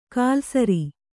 ♪ kālsari